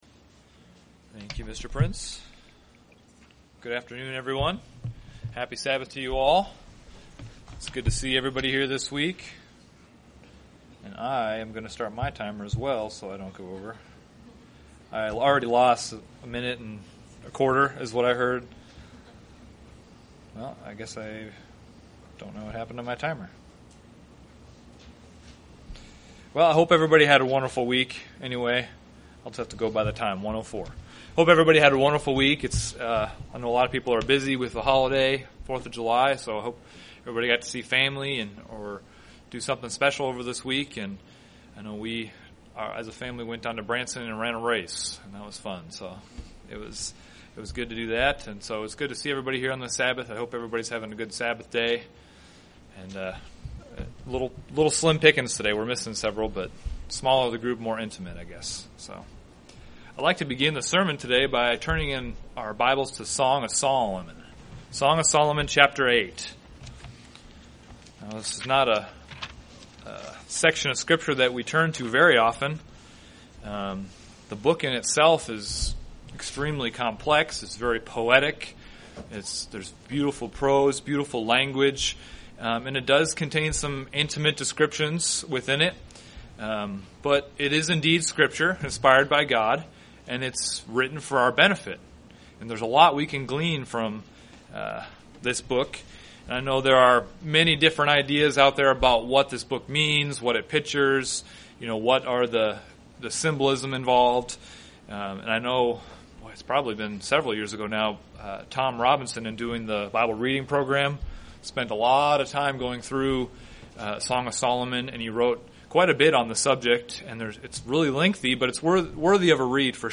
Sermon about the qualities of first love.